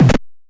电池玩具猕猴桃 " 踢汤姆
描述：从一个简单的电池玩具中录制的，是用一个猕猴桃代替的音调电阻！
Tag: 音乐学院-incongrue 电路弯曲 汤姆